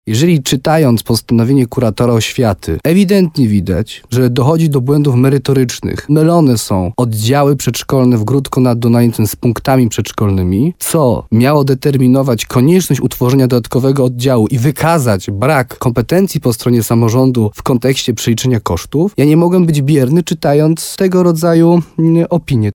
Wójt gminy Gródek nad Dunajcem Jarosław Baziak złożył już odwołanie. W programie Słowo za Słowo na antenie RDN Nowy Sącz podkreślał, że decyzja kuratora nie była jego zdaniem oparta o podstawy prawa.